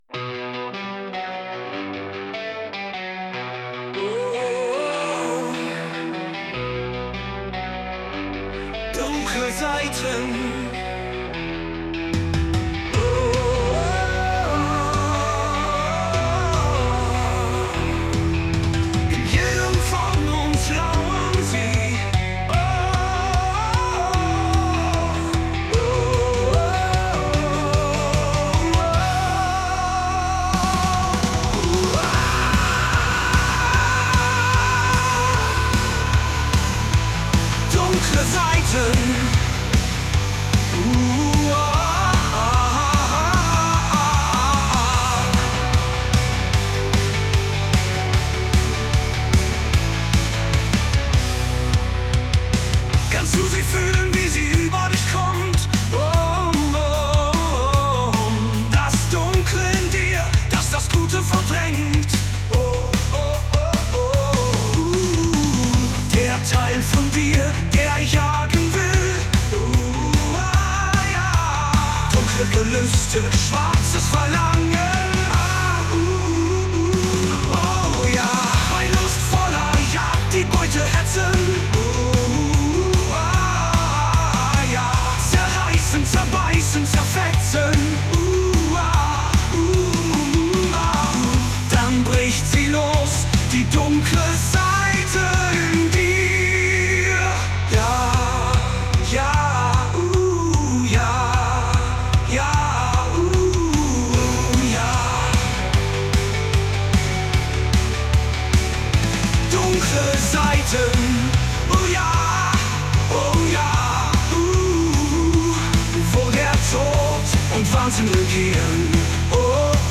Veröffentlicht: 2024 Genre: Rock